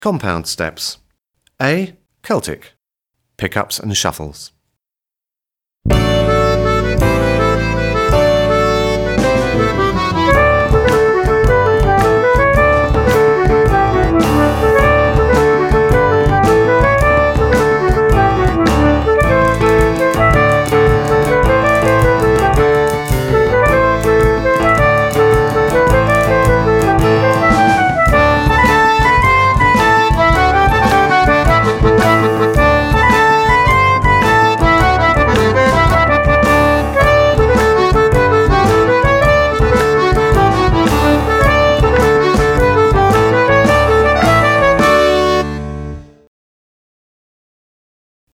Compound Step A (Celtic)